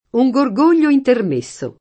gorgoglio [ g or g1 l’l’o ] s. m. («il gorgogliare»); pl. -gli